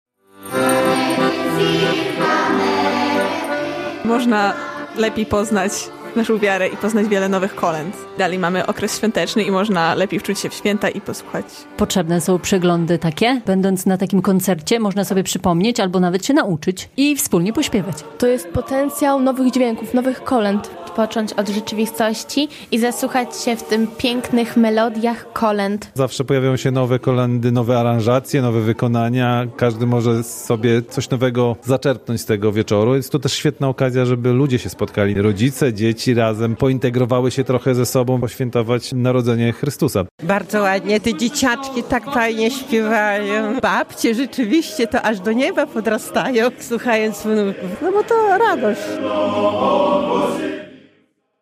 Można było usłyszeć kolędy w różnych językach. W Operze i Filharmonii Podlaskiej w czwartek (11.01) odbył się pierwszy z dwóch dorocznych wieczorów kolęd prawosławnych zorganizowanych po raz 42. przez prawosławną diecezję białostocko-gdańską.